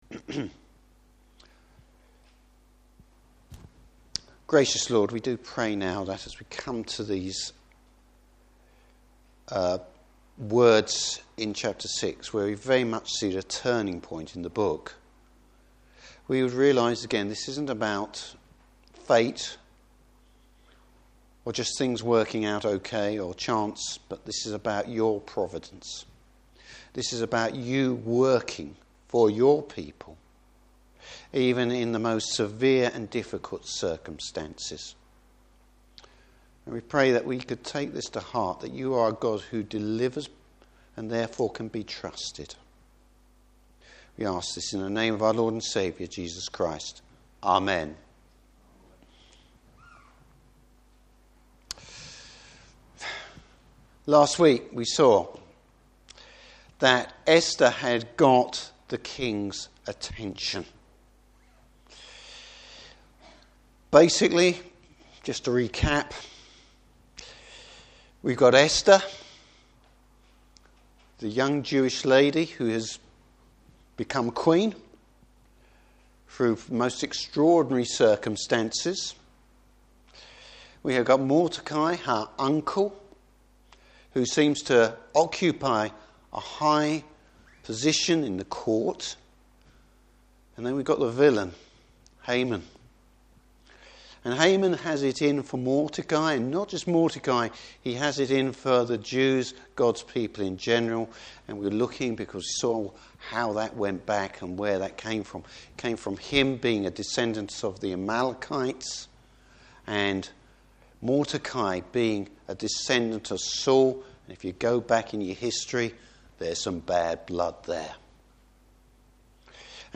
Service Type: Evening Service A sleepless night helps shape events in Esther’s favour.